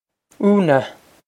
Úna Oo-nuh
Oo-nuh
This is an approximate phonetic pronunciation of the phrase.